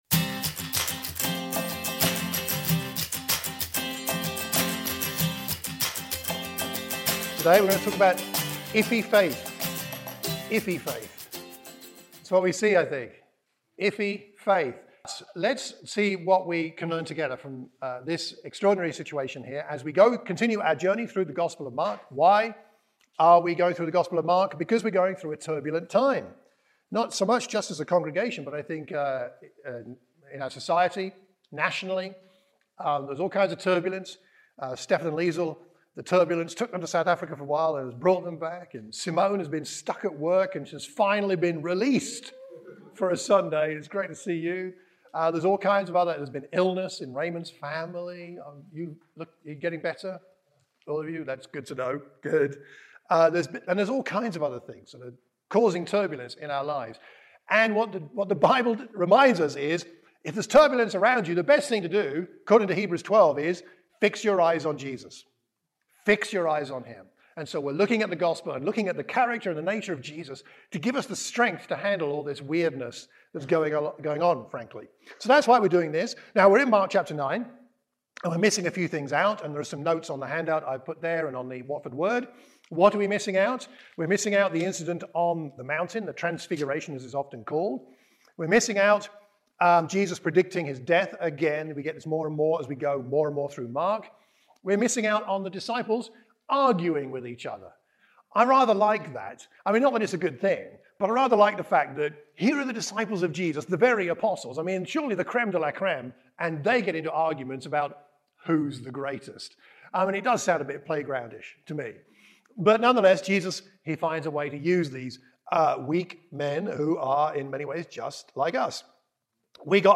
In this sermon for the Watford Church of Christ, we explore the issue of iffy faith. How much faith do we need, and how should we react when something appears to be impossible?